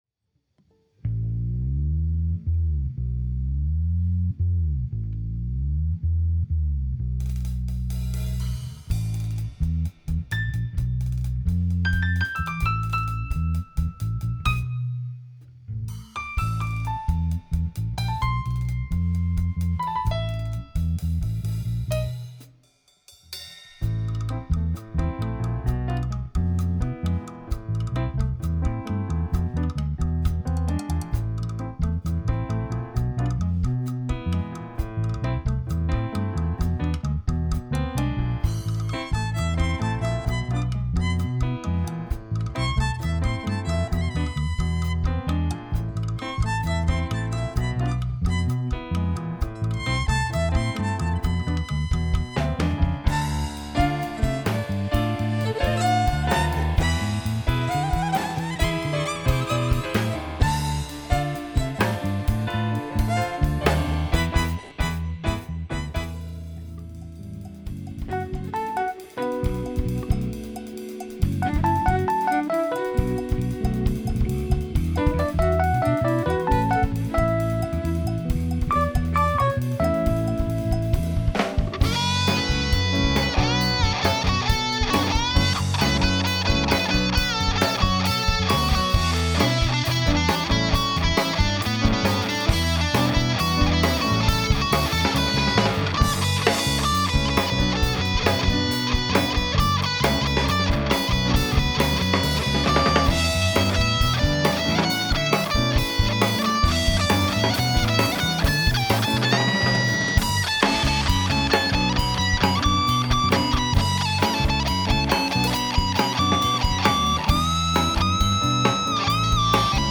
groupe de jazz fusion